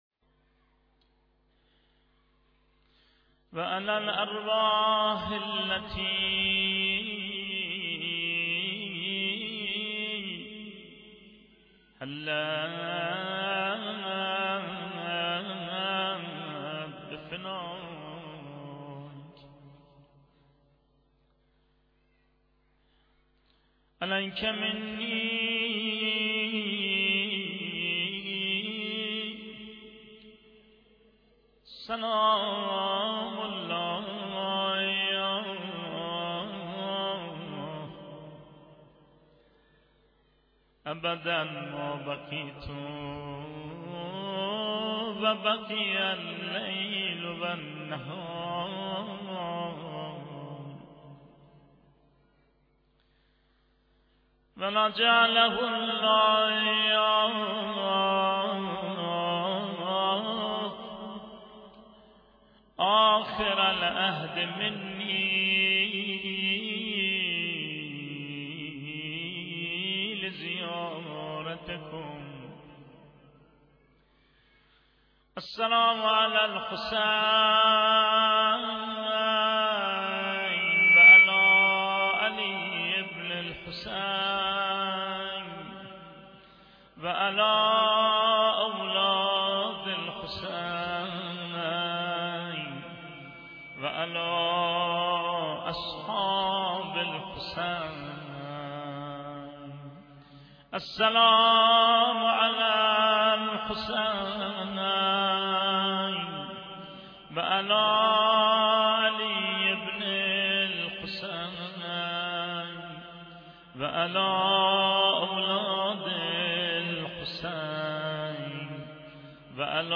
صوت/حاج صادق آهنگران/روضه خوانی و سینه زنی